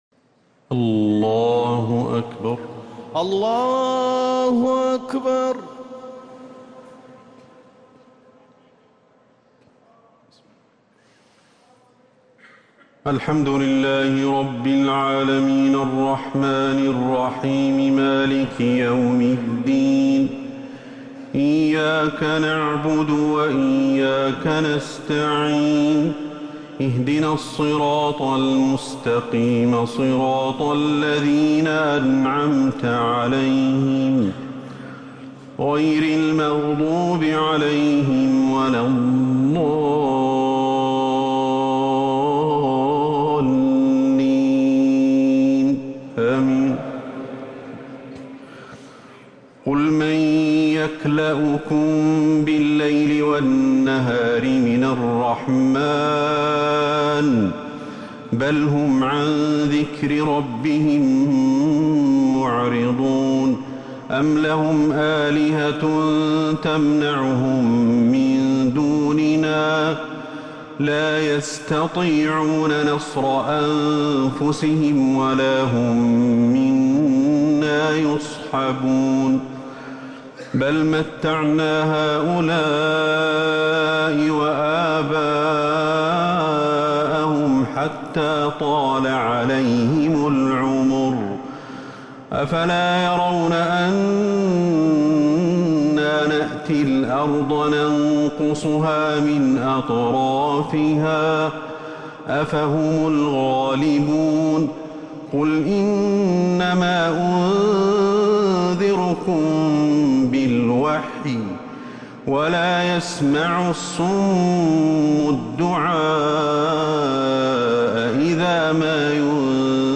تهجد ليلة 21 رمضان 1447هـ من سورتي الأنبياء (42-112) و الحج (1-16) | Tahajjud 21st night Ramadan1447H Surah Al-Anbiya and Al-Hajj > تراويح الحرم النبوي عام 1447 🕌 > التراويح - تلاوات الحرمين